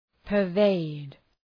Προφορά
{pər’veıd}